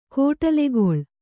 ♪ kōṭalegoḷ